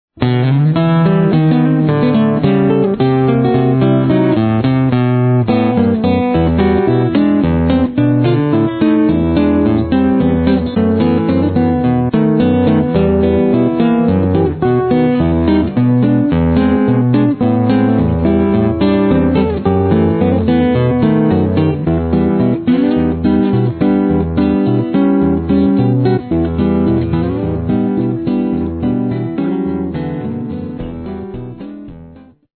guitare (nylon & électrique)